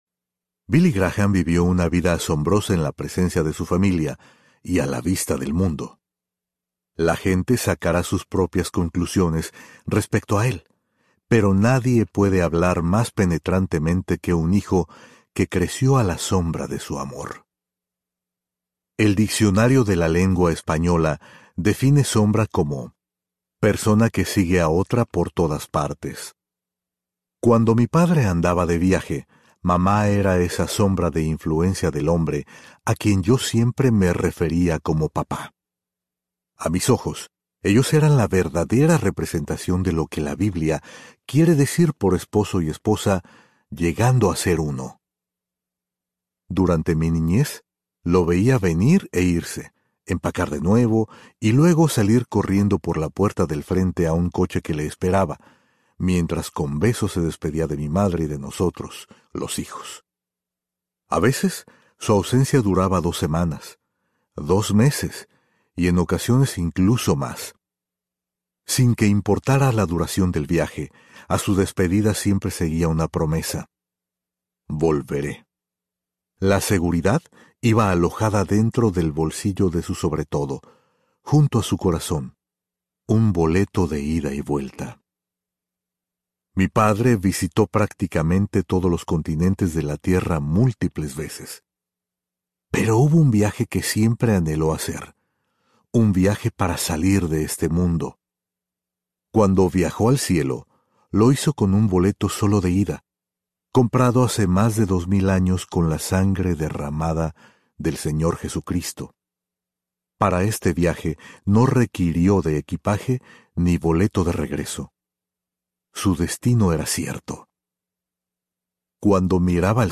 Por los ojos de mi padre Audiobook
12.6 Hrs. – Unabridged